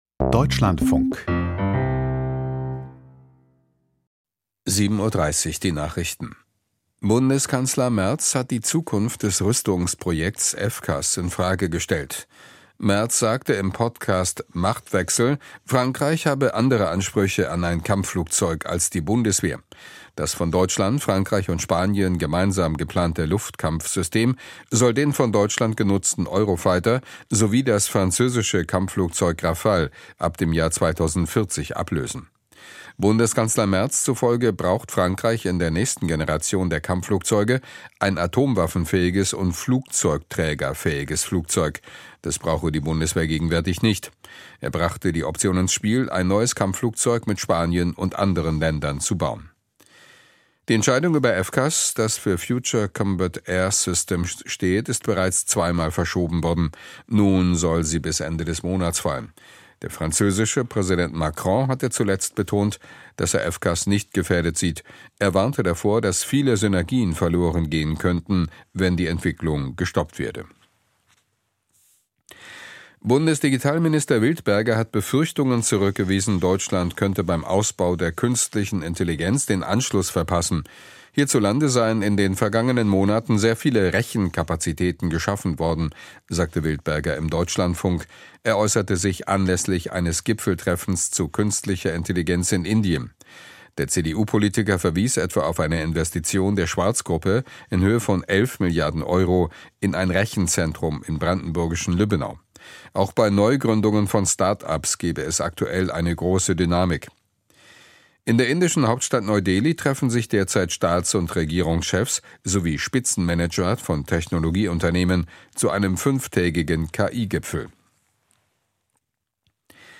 Die Nachrichten vom 18.02.2026, 07:30 Uhr